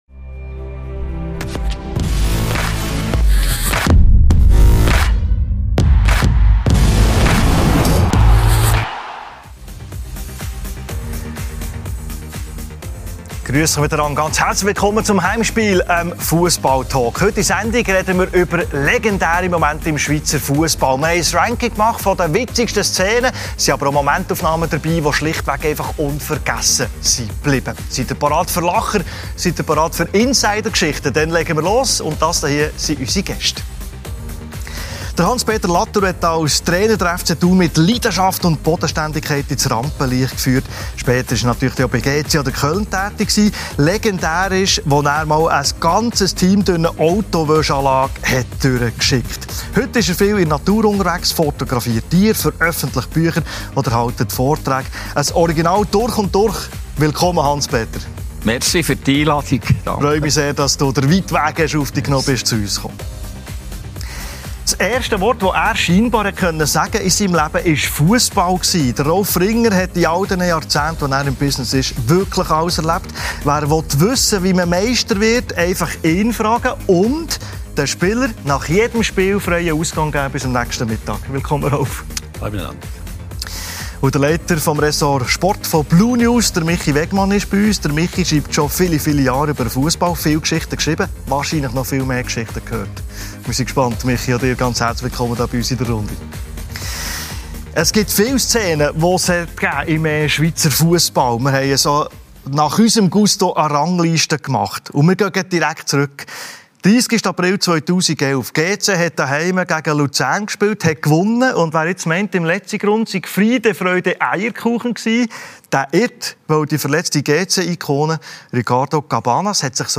«Das isch ä Gränni!»: Legendäre Super-League-Momente ~ Heimspiel ⎥ Der Fussball-Talk Podcast